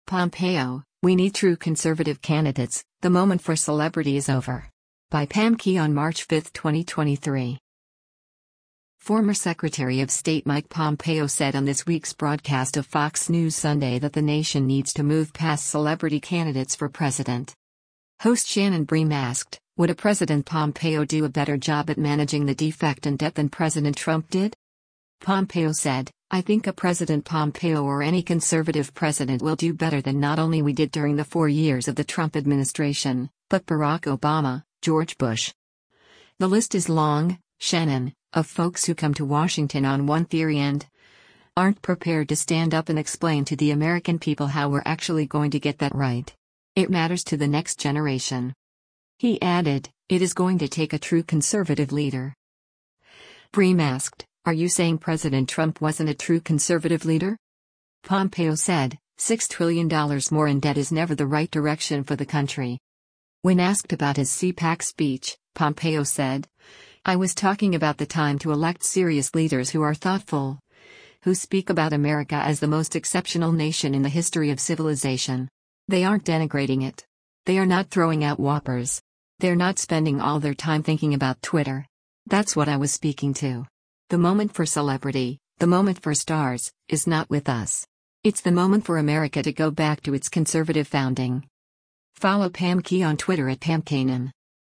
Former Secretary of State Mike Pompeo said on this week’s broadcast of “Fox News Sunday” that the nation needs to move past celebrity candidates for president.
Host Shannon Bream asked, “Would a President Pompeo do a better job at managing the defect and debt than President Trump did?”